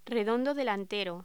Locución: Redondo delantero
Sonidos: Voz humana